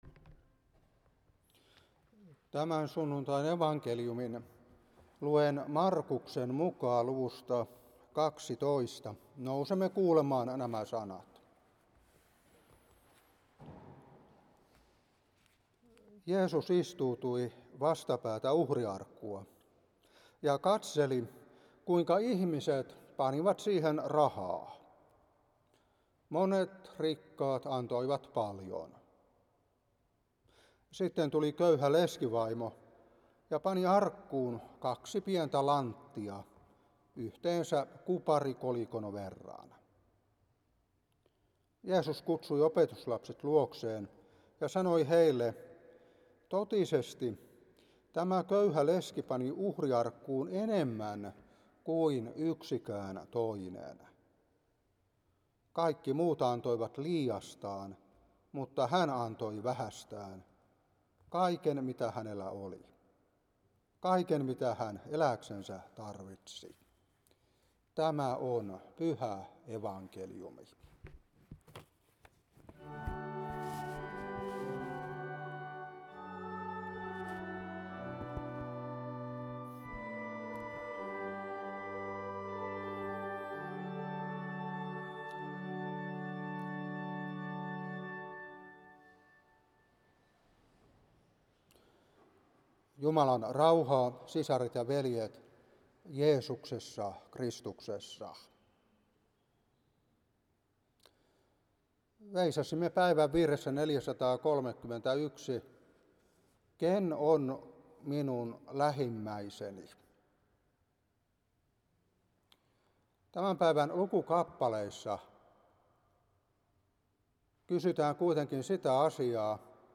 Saarna 2024-8. Room,13:8-10. Mark.12:41-44.